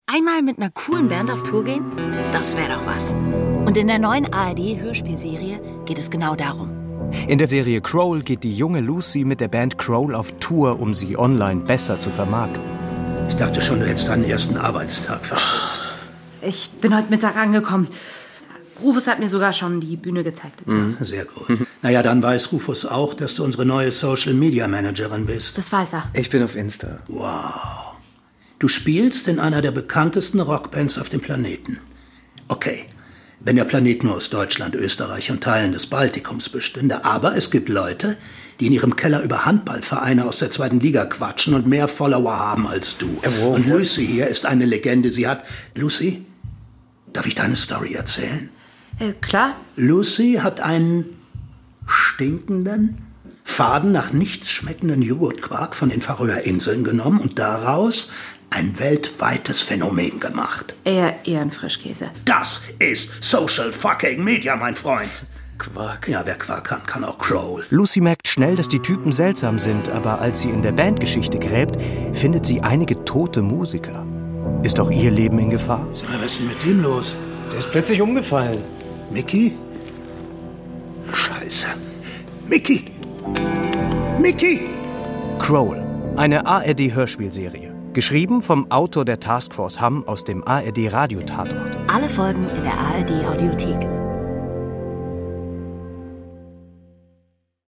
Eine Crime-Serie in 7 Folgen.